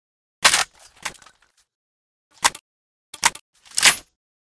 toz34_reload_obrez.ogg